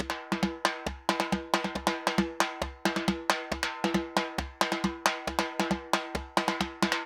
Timba_Merengue 136_1.wav